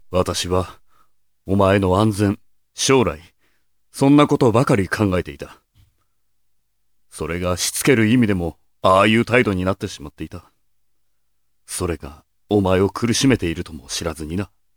性別：男